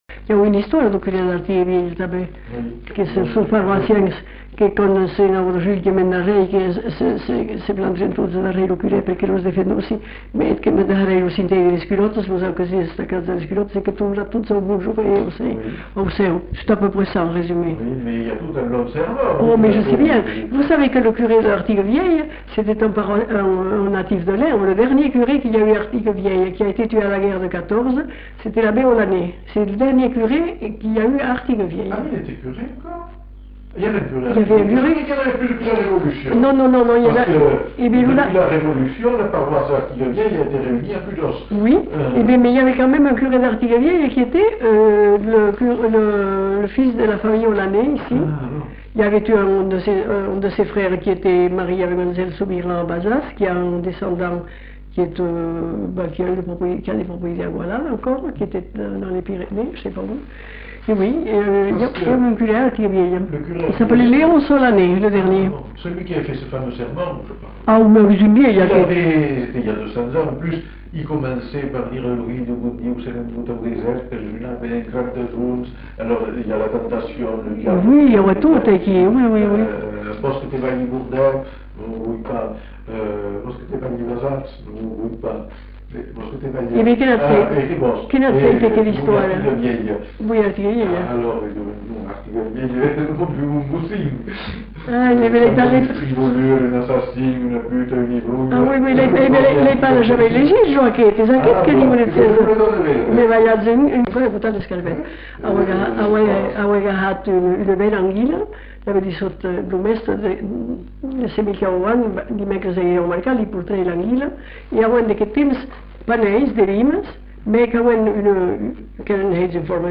Lieu : Lerm-et-Musset
Genre : conte-légende-récit
Effectif : 1
Type de voix : voix de femme
Production du son : parlé